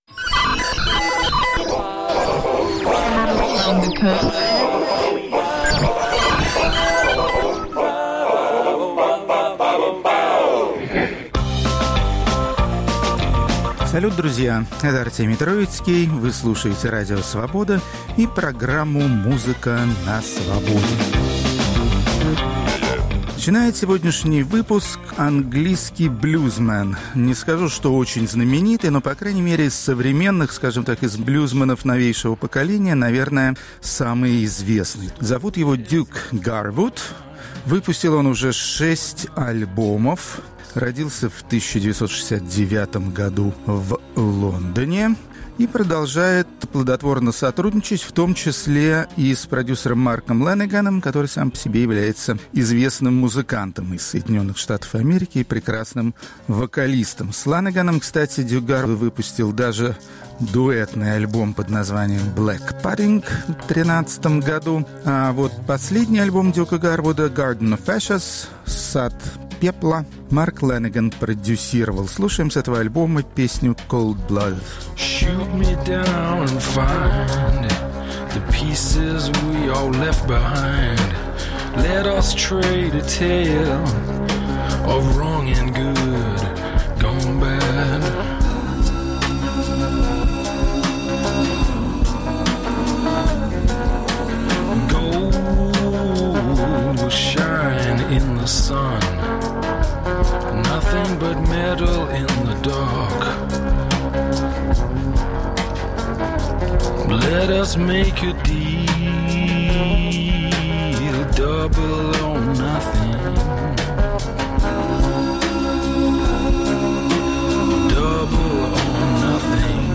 Исполнители, работающие в стиле клезмер, это традиционная музыка восточноевропейских евреев. Рок-критик Артемий Троицкий изучает еврейское музыкальное наследие.